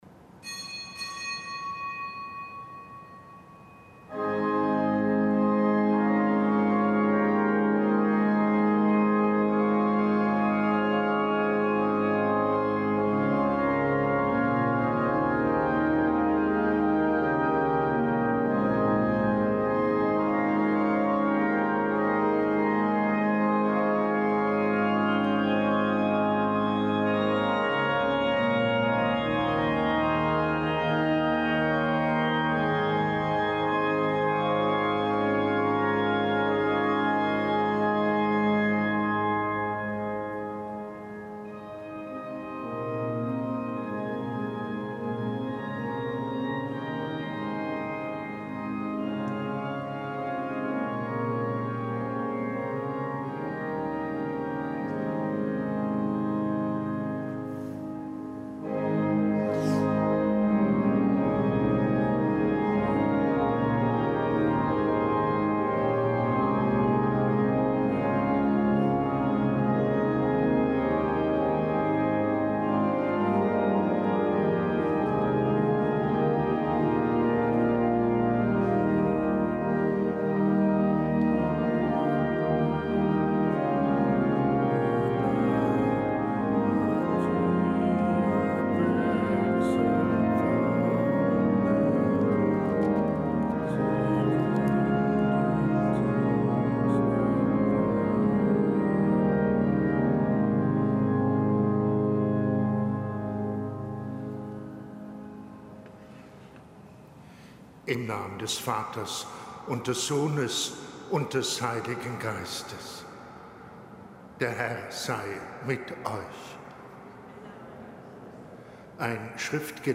Gottesdienst
Kapitelsmesse aus dem Kölner Dom am Gedenktag Hl. Hieronymus, Priester, Kirchenlehrer.